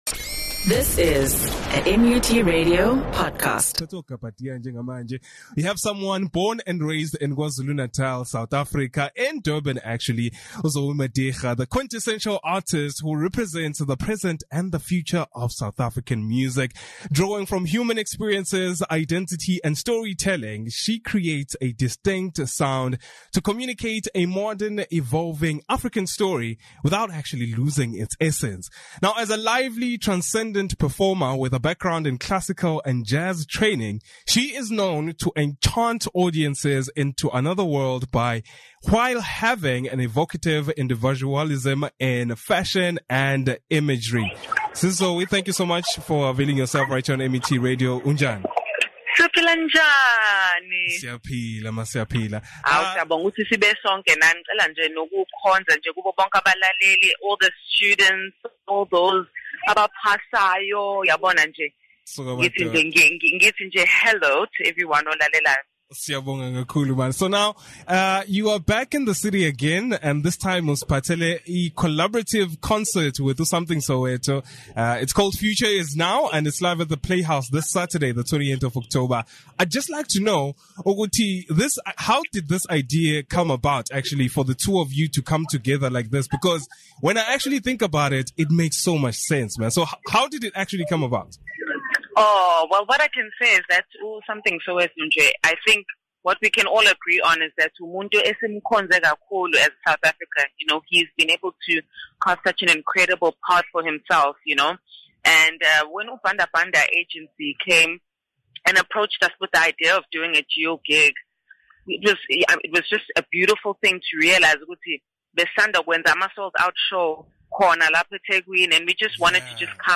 Interview with Zoe Modiga